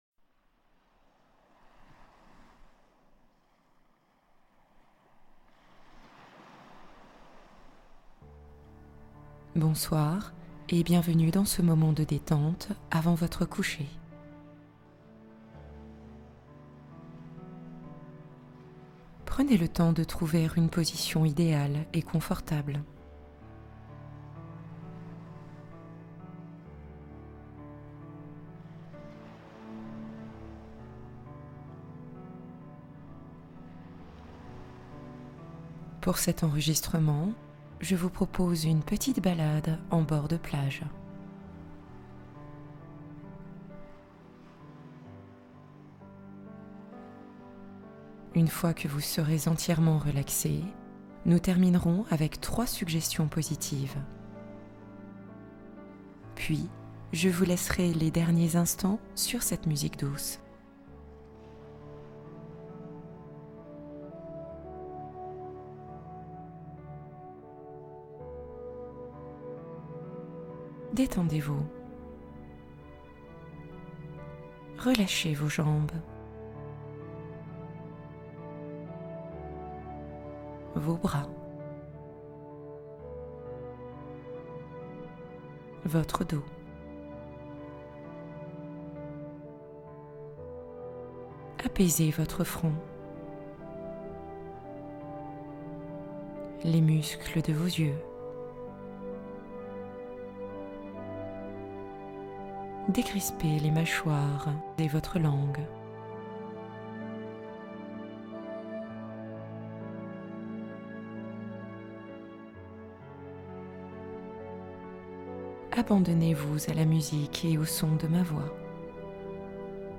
Libérez-vous du poids mental qui vous épuise | Méditation nocturne détox cérébrale